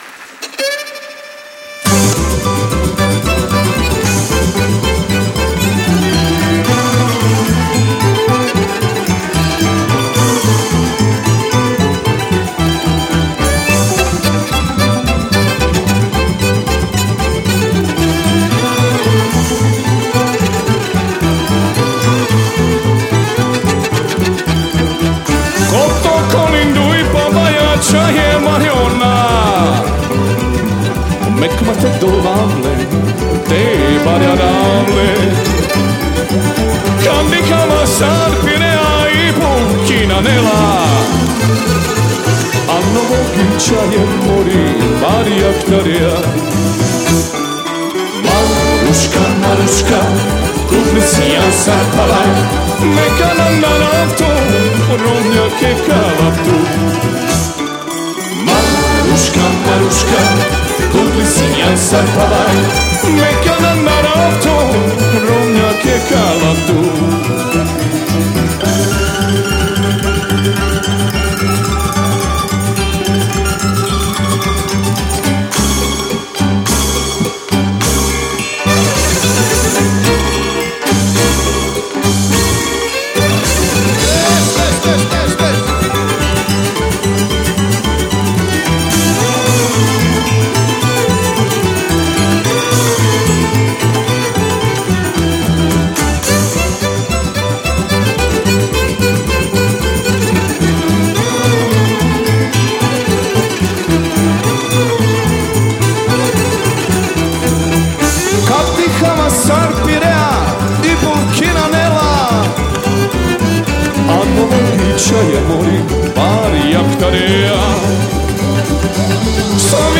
vokal, bugarija
violina, bisernica, brač
tamburaško čelo
kontrabas
violina, brač, kitara
cimbale
tolkala